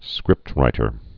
(skrĭptrītər)